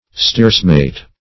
Search Result for " steersmate" : The Collaborative International Dictionary of English v.0.48: Steersmate \Steers"mate\ (st[=e]rz"m[=a]t`), n. [Steer a rudder + mate a companion.]